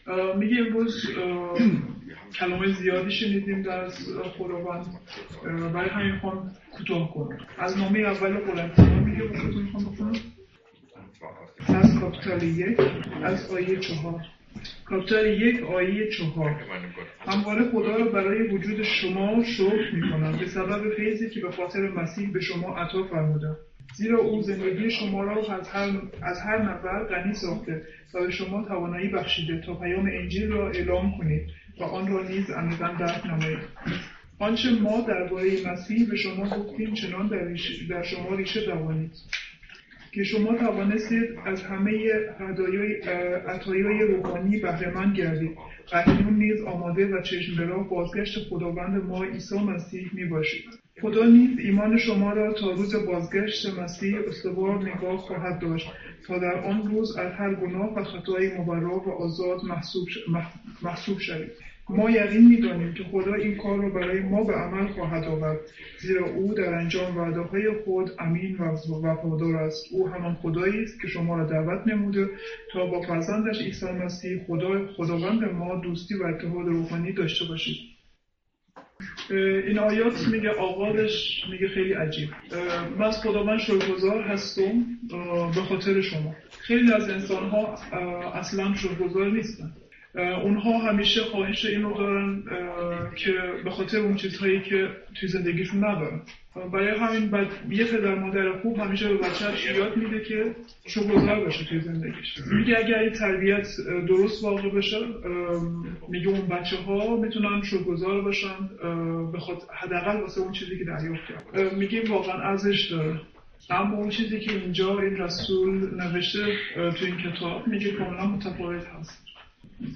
Korinther 1,4-9 | Übersetzung in Farsi